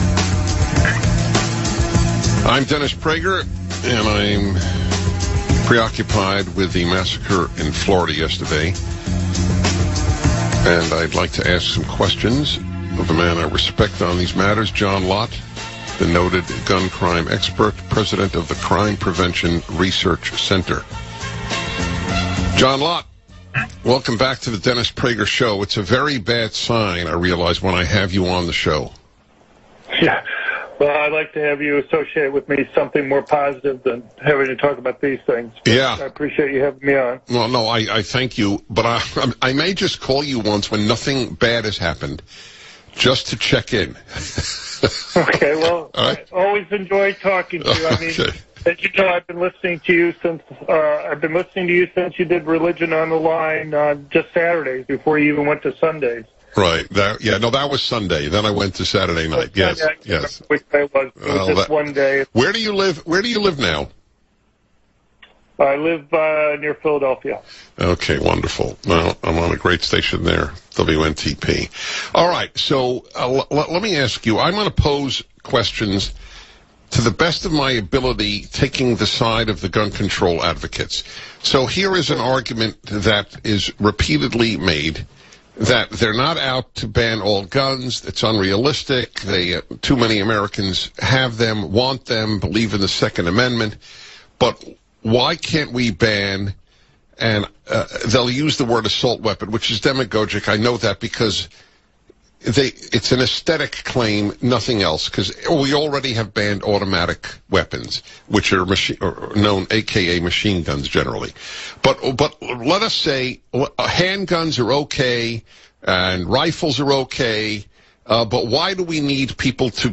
Dr. John Lott was on the Dennis Prager Radio Show to discuss assault weapon bans and comparing crime in the US to Europe.